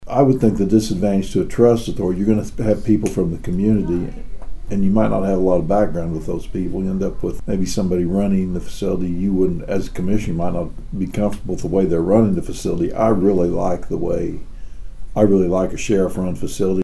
During the commissioner’s report, Chairmen Mike Dunlap spoke at length about a workshop he attended where there was a discussion on who should run jails.